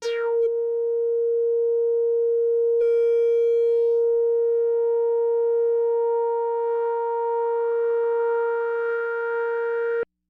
标签： ASharp5 MIDI音符-82 Korg的-Z1 合成器 单票据 多重采样
声道立体声